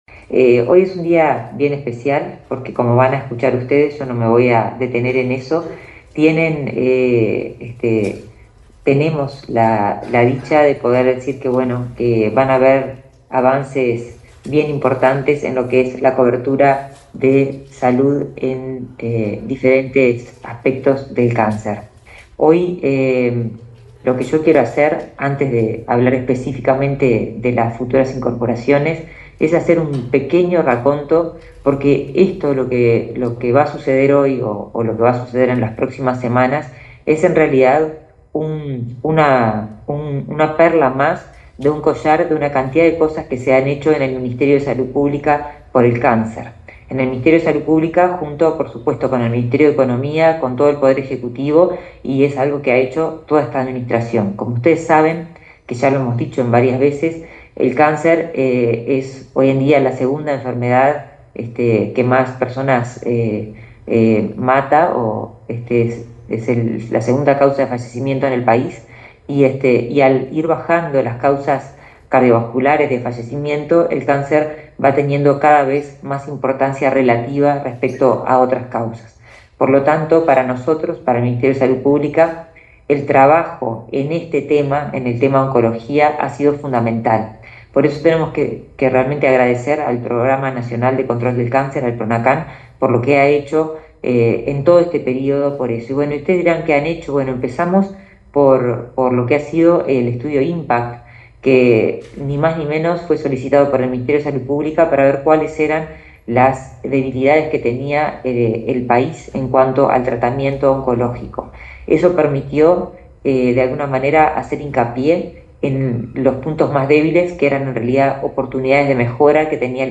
Palabra de autoridades en acto en el MSP
Palabra de autoridades en acto en el MSP 10/09/2024 Compartir Facebook X Copiar enlace WhatsApp LinkedIn El Ministerio de Salud Pública (MSP) anunció la incorporación de nuevos medicamentos a las prestaciones del Fondo Nacional de Recursos (FNR). En la oportunidad, participaron, la ministra Karina Rando (vía zoom); su par de Economía, Azucena Arbeleche; y el subsecretario de Salud Pública, José Luis Satdjian.